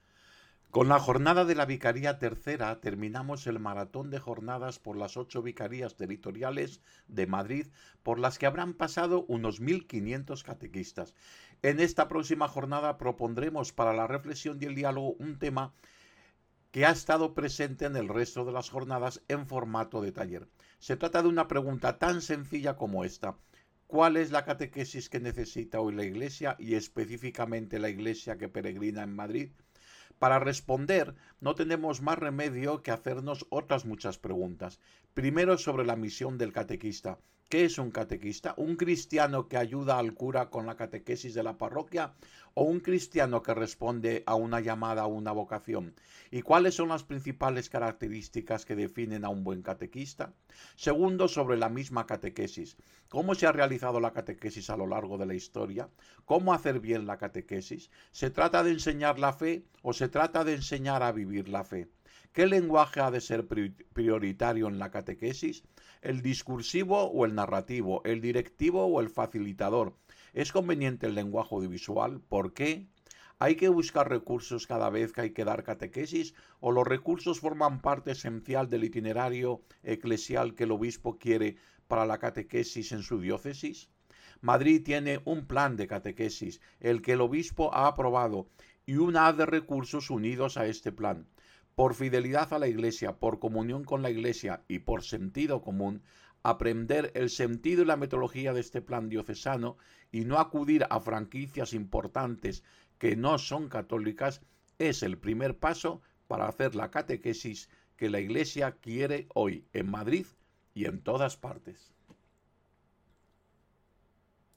EXPLICACIÓN PARA LA CADENA COPE DE LA TEMÁTICA DE LA INTERVENCIÓN: